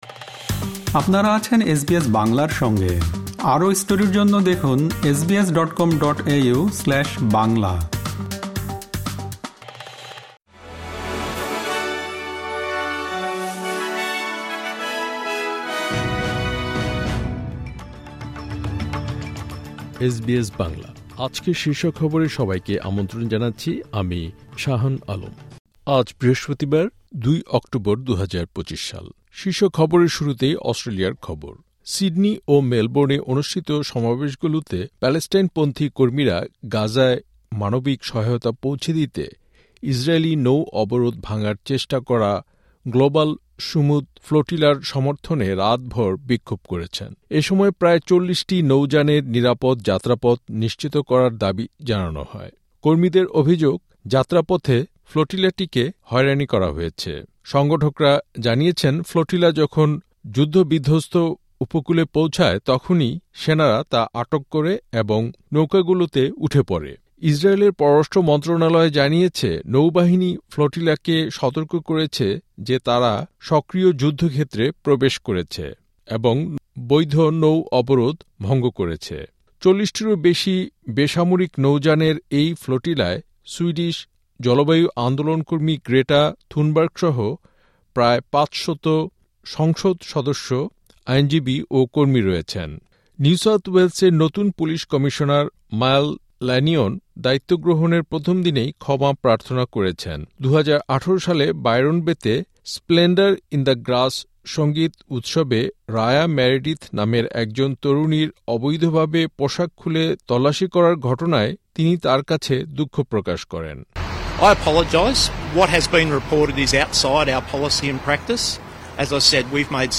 অস্ট্রেলিয়ার জাতীয় ও আন্তর্জাতিক সংবাদের জন্য আজকের এসবিএস বাংলা শীর্ষ খবর শুনতে উপরের অডিও-প্লেয়ারটিতে ক্লিক করুন।